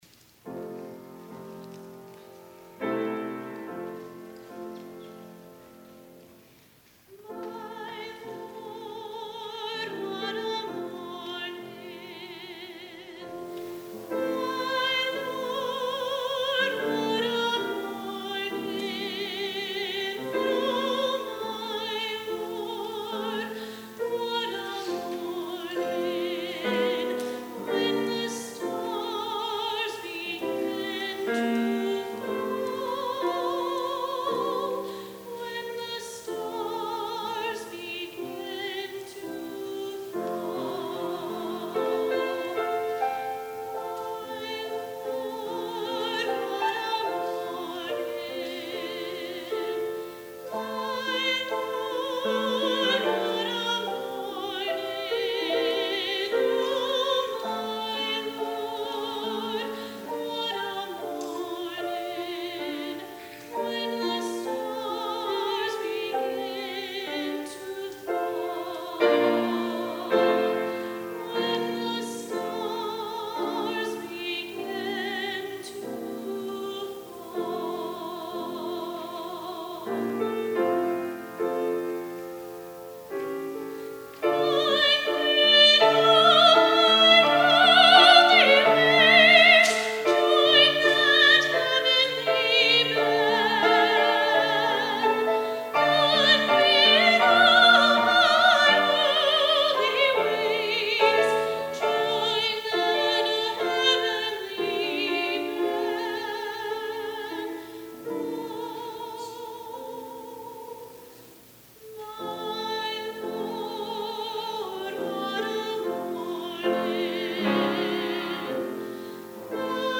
SOLO My Lord, What a Mornin’
soprano
piano